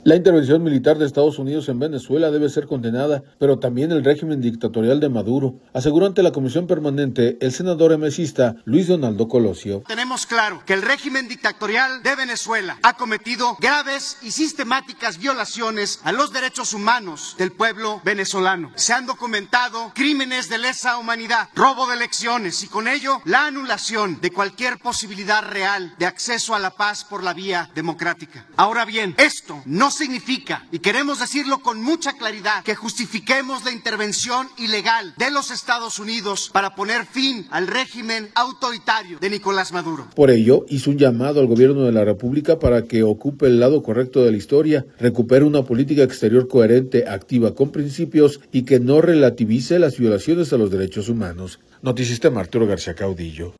La intervención militar de Estados Unidos en Venezuela debe ser condenada, pero también el régimen dictatorial de Maduro, aseguró ante la Comisión Permanente el senador emecista, Luis Donaldo Colosio.